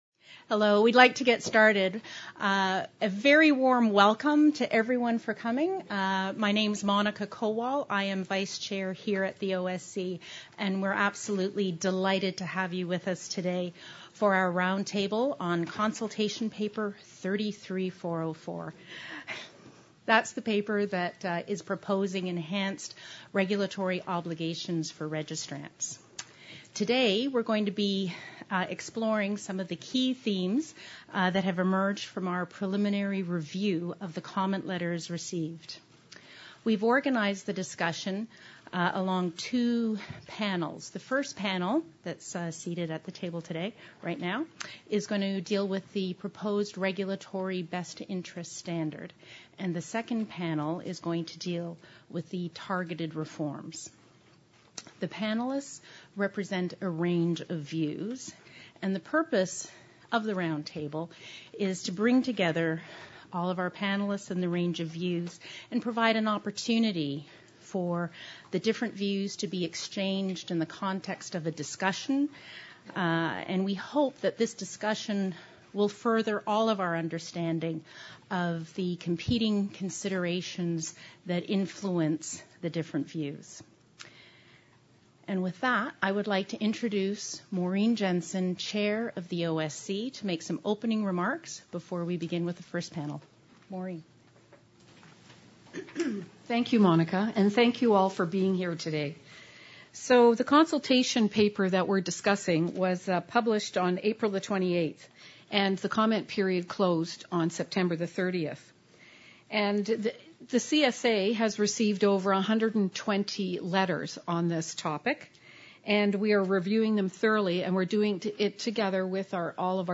CSA Roundtable on Proposed Best Interest and Targeted Reforms
Introduction and Opening Remarks (